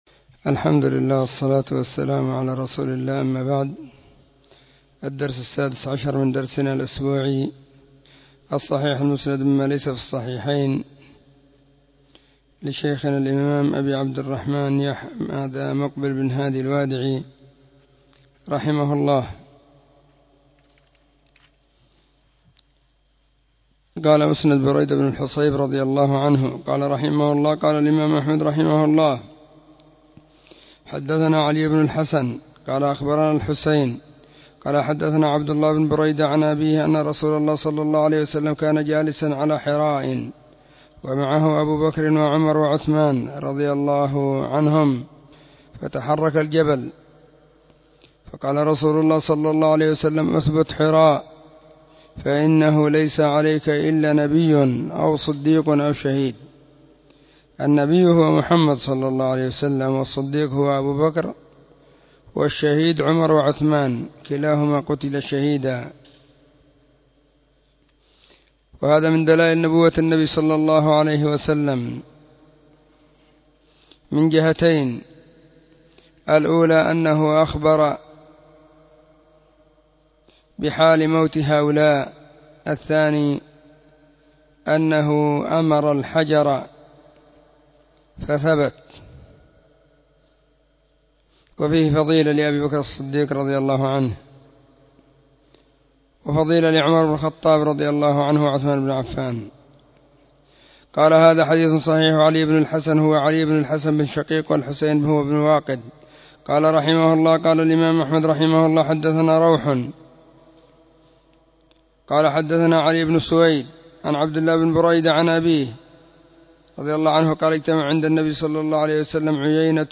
الصحيح_المسند_مما_ليس_في_الصحيحين_الدرس_16.mp3